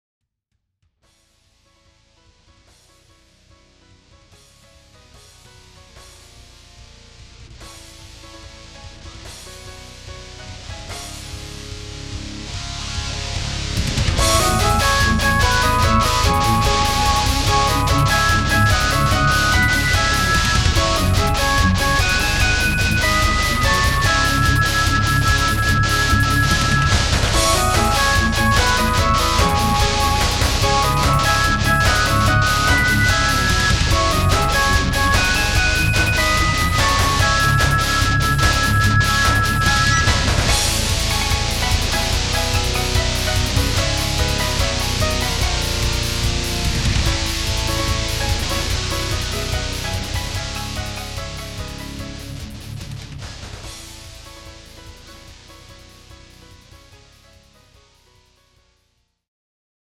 「和」「雅」といった叙情的なものとElectricGuitarサウンドの調和・融合を目指した
Play All Instruments